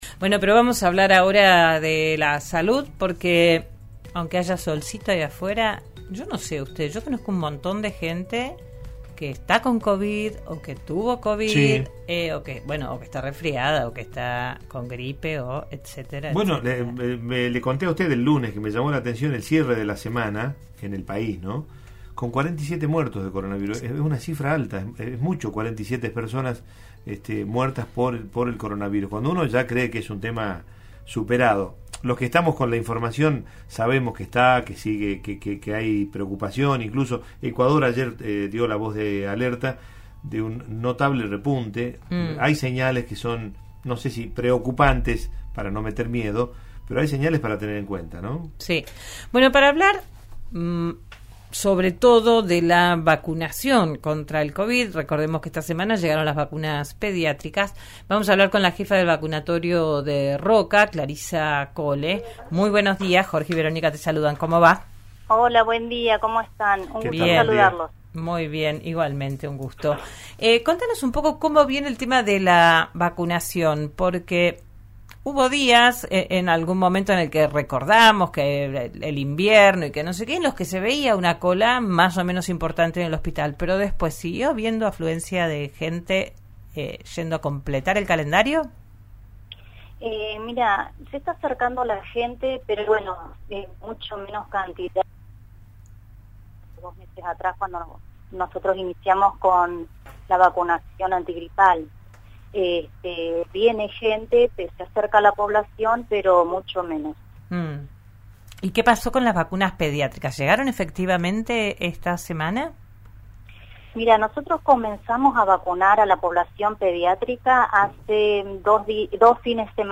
en «Digan lo que digan» por RN RADIO: